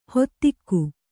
♪ hottikku